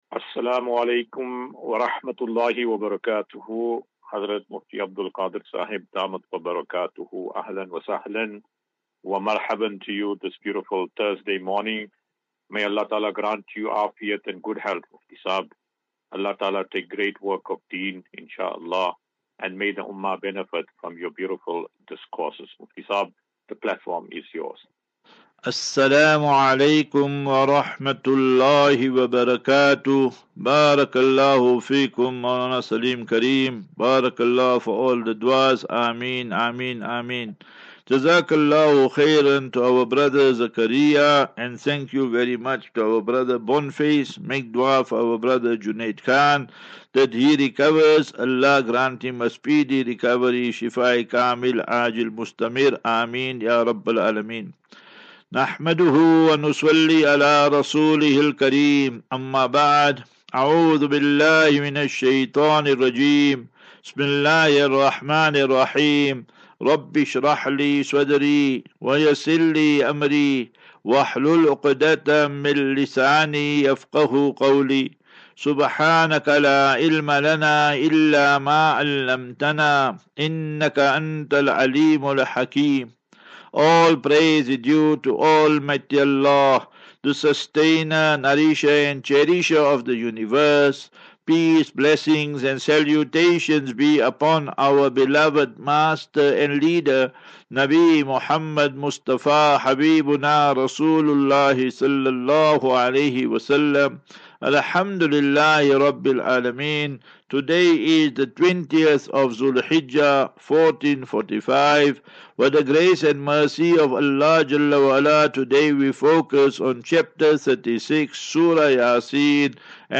View Promo Continue Install As Safinatu Ilal Jannah Naseeha and Q and A 27 Jun 27 June 2024.